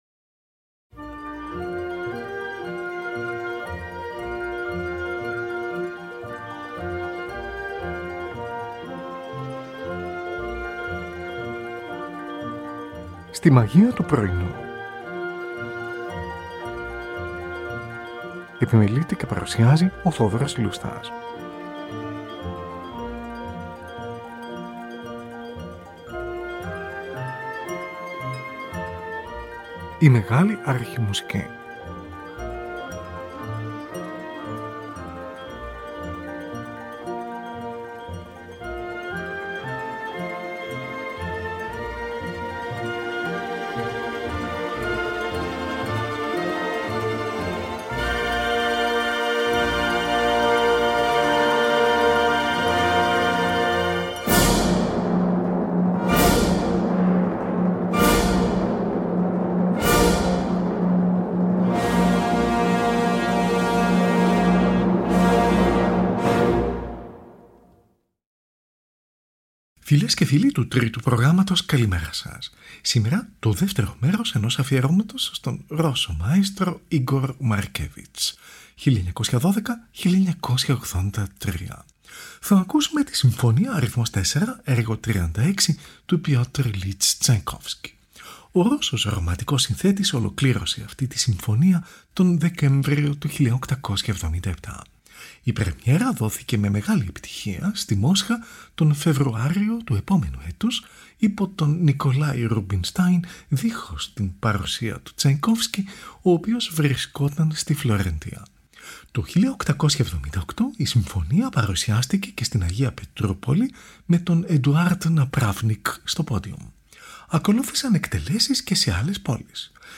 Την Ορχήστρα Gewandhaus της Λειψίας διευθύνει ο Igor Markevitch, από ζωντανή ηχογράφηση, στις 12 Μαΐου 1978 .